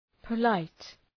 Προφορά
{pə’laıt}
polite.mp3